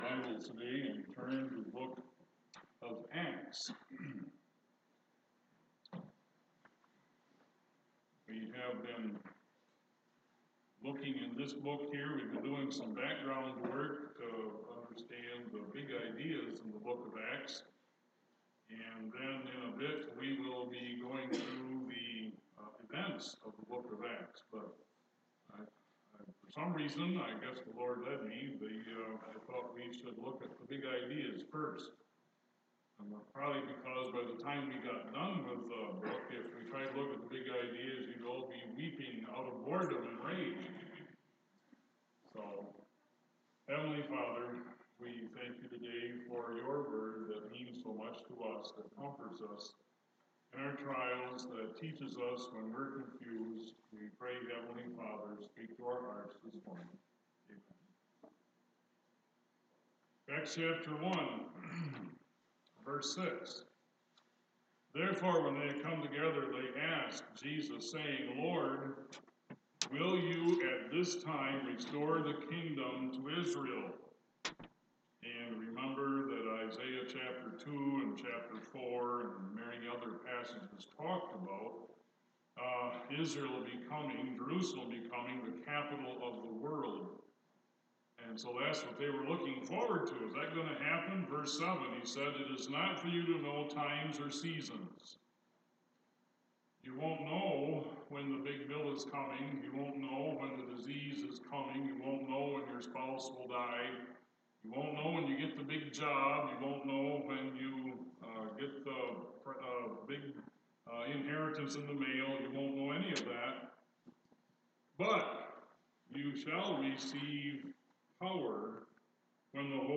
Acts 1 Prayer | First Baptist Church of Petoskey Sunday Morning Bible Teaching